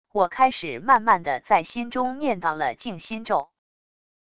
我开始慢慢的在心中念叨了静心咒wav音频生成系统WAV Audio Player